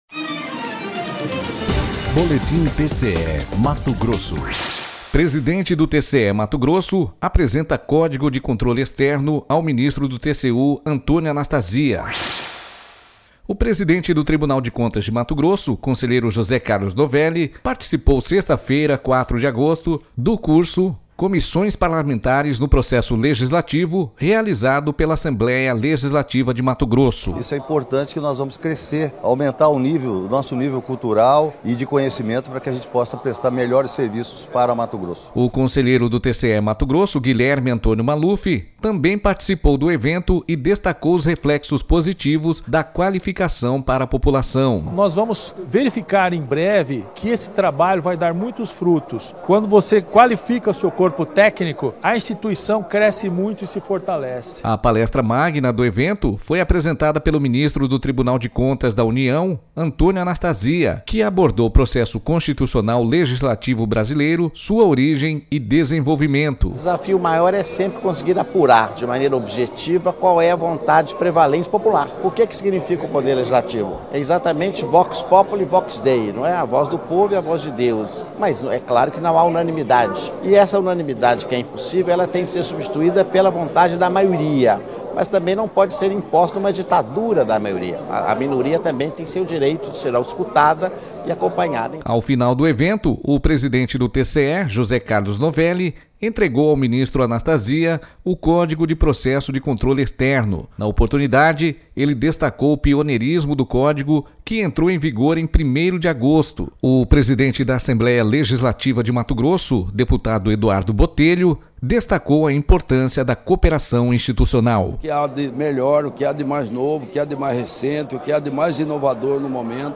Sonora: José Carlos Novelli – conselheiro presidente do TCE-MT
Sonora: Antônio Anastasia - ministro do TCU
Sonora: Eduardo Botelho - deputado presidente da ALMT